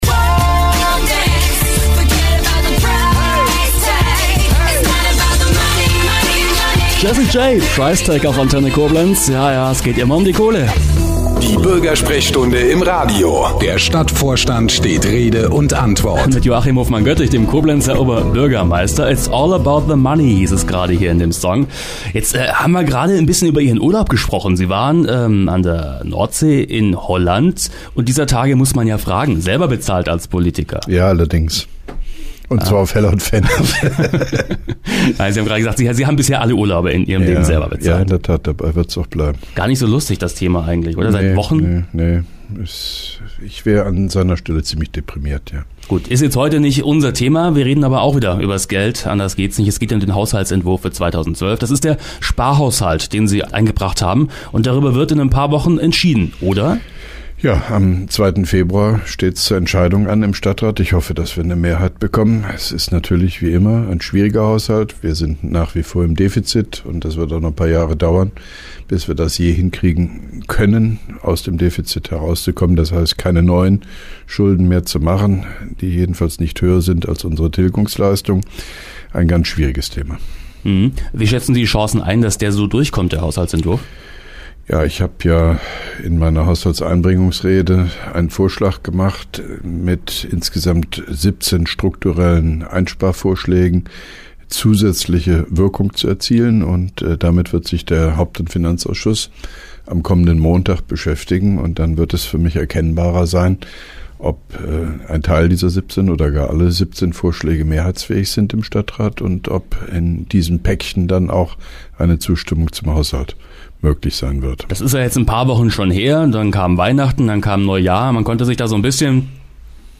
(3) Koblenzer Radio-Bürgersprechstunde mit OB Hofmann-Göttig 17.01.2012